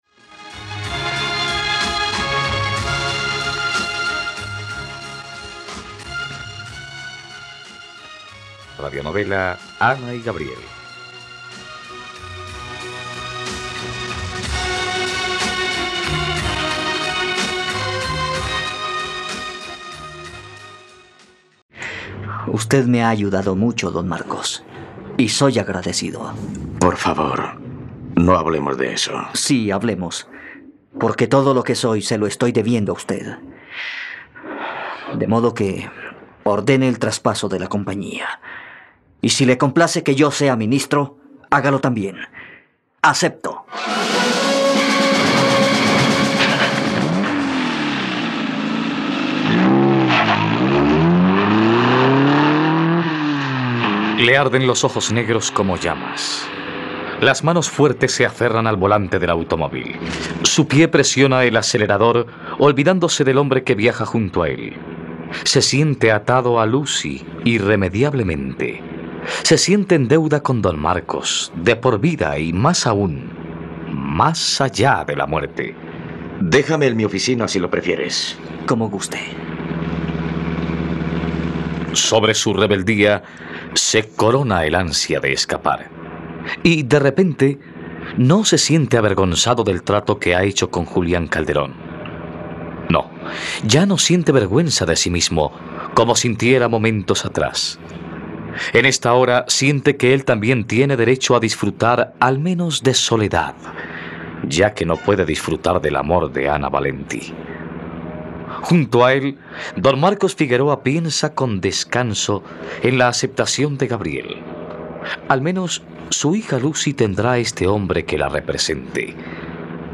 Ana y Gabriel - Radionovela, capítulo 76 | RTVCPlay